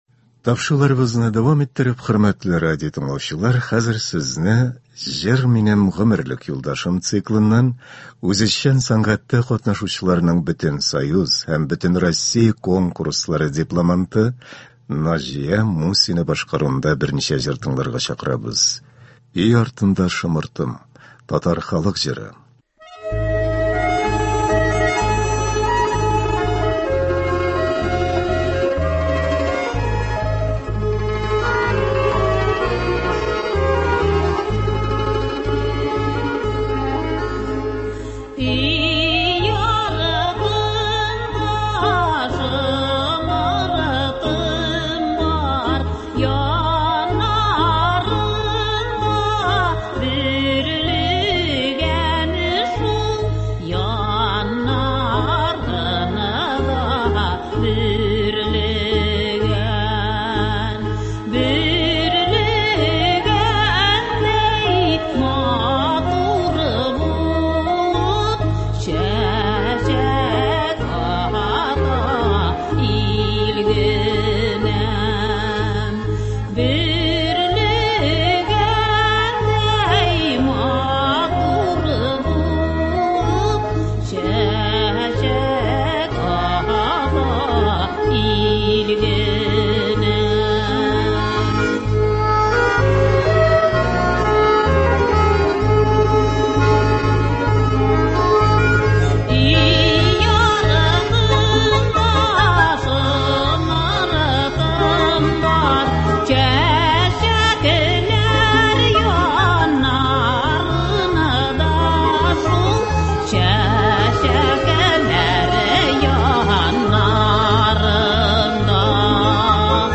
Үзешчән башкаручылар чыгышы.
Концерт (27.05.24)